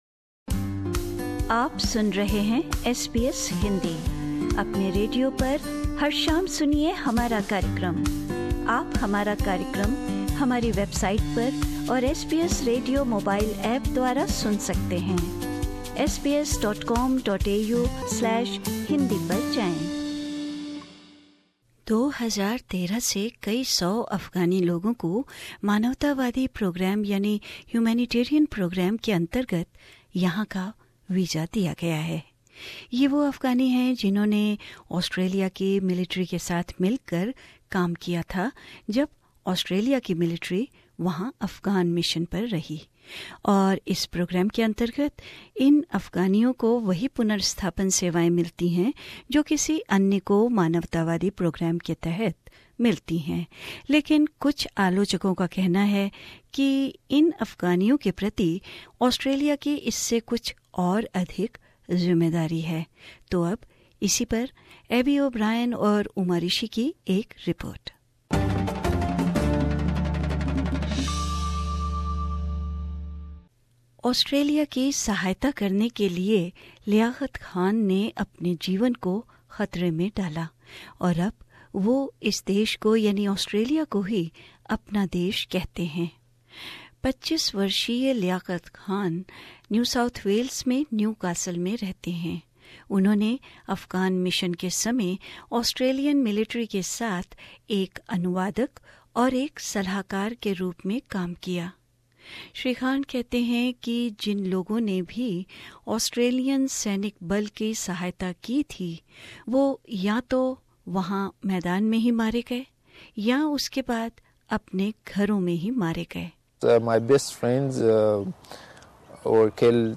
A feature